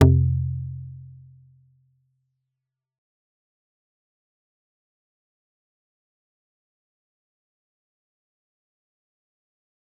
G_Kalimba-F2-f.wav